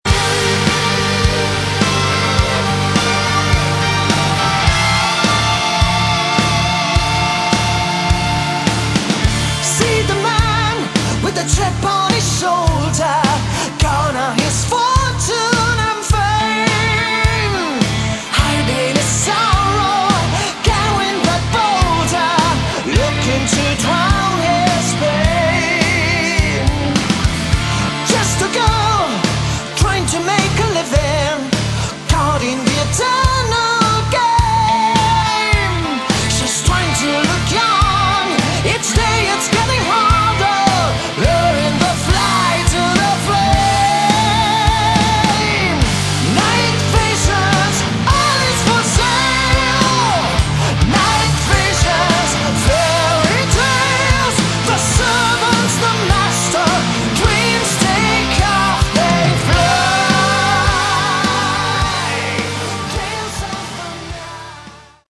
Category: Melodic Metal / Prog / Power Metal
guitars
keyboards
drums
bass
vocals
violin